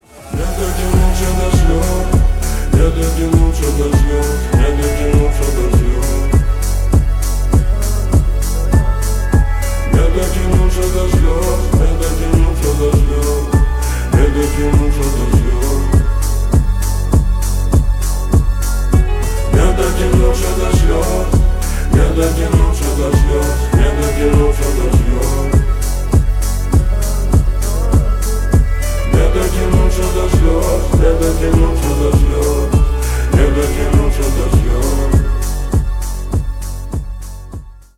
• Качество: 320 kbps, Stereo
Ремикс
клубные